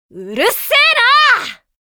山海战记_苏轼_日文台词_05.mp3